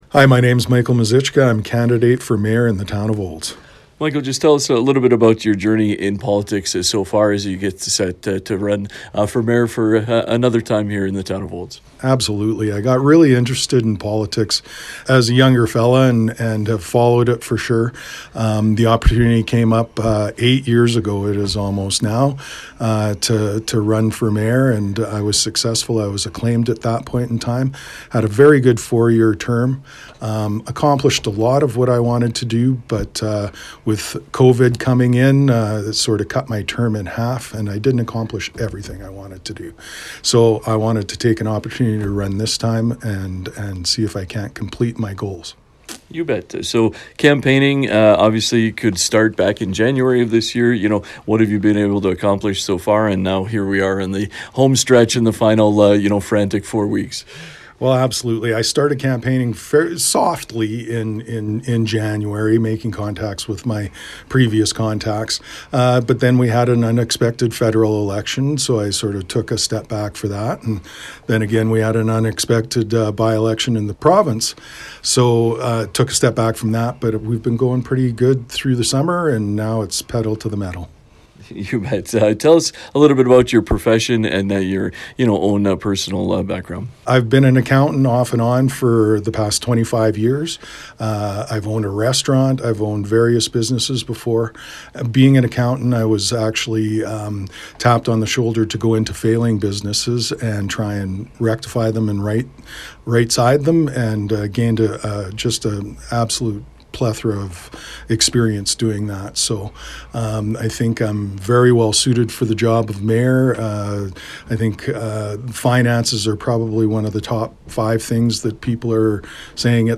Listen to 96.5 The Ranch’s conversation with Michael Muzychka.